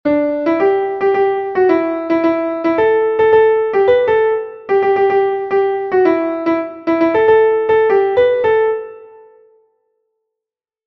Dantzakoa
Espainiar kantu tradizionala.
AB